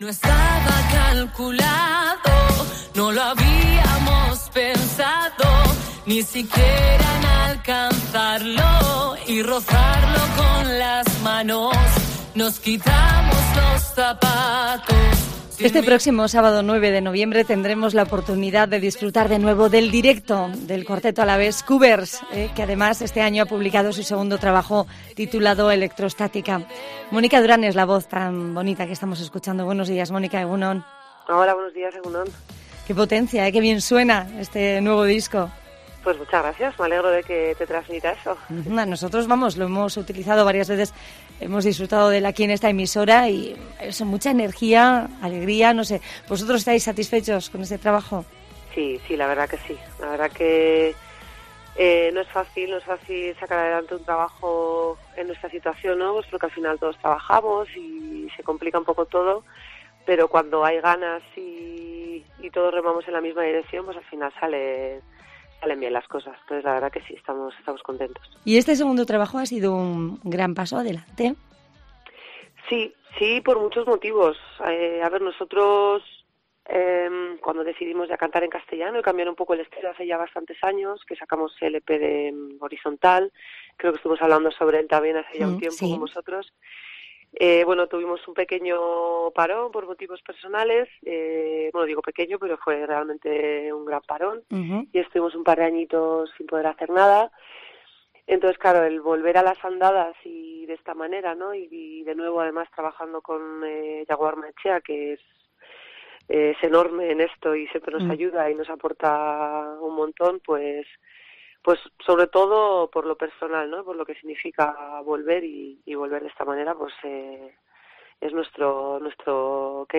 Entrevista a la banda vitoriana KUBERS